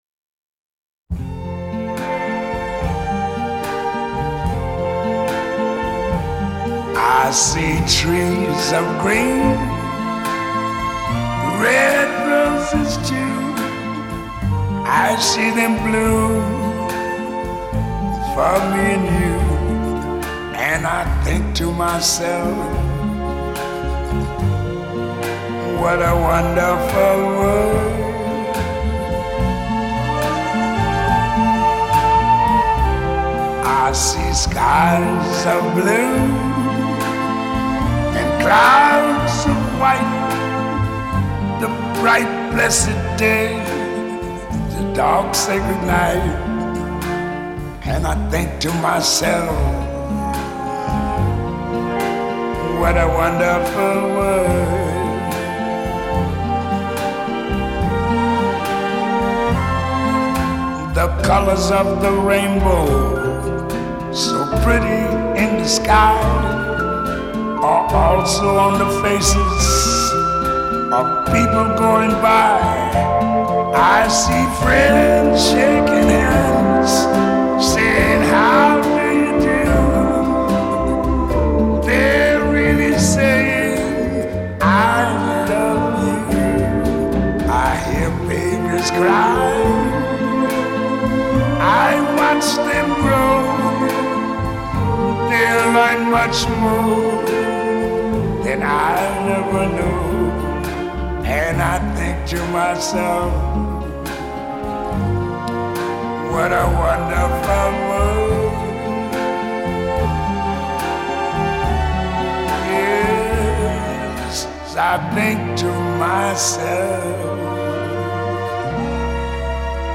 Джаз...